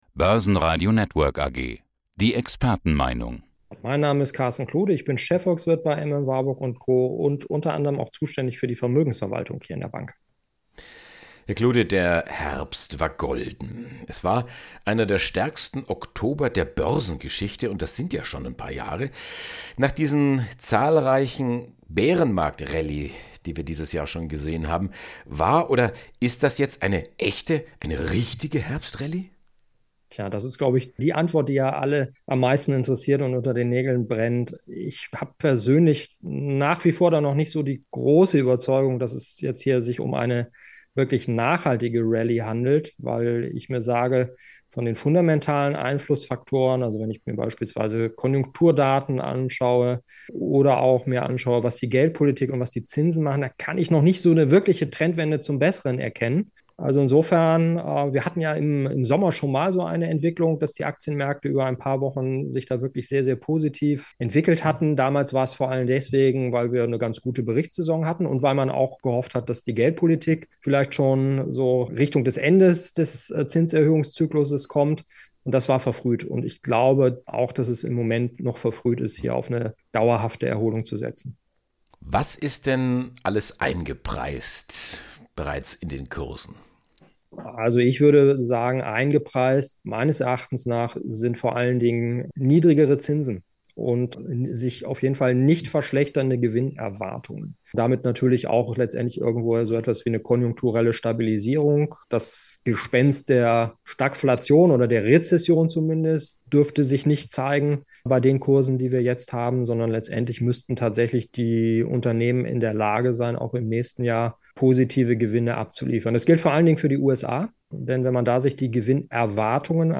Wie der Experte die aktuelle Berichtssaison in Europa und den USA einschätzt und welche weiteren Maßnahmen die Europäische Zentralbank im Kampf gegen die Inflation ergreifen sollte, erfahren Sie im Interview.